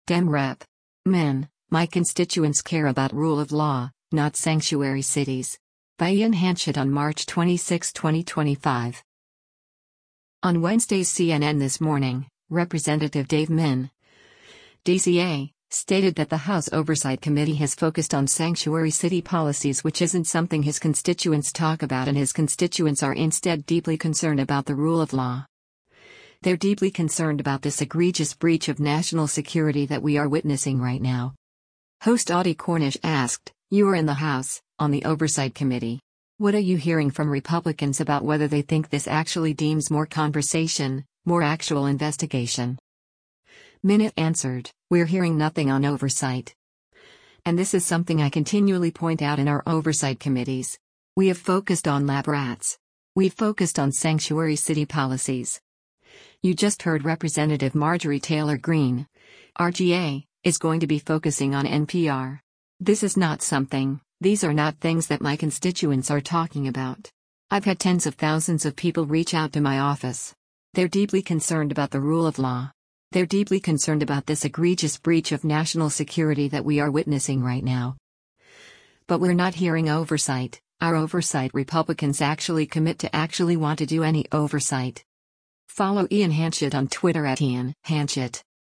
Host Audie Cornish asked, “You are in the House, on the Oversight Committee. What are you hearing from Republicans about whether they think this actually deems more conversation, more actual investigation?”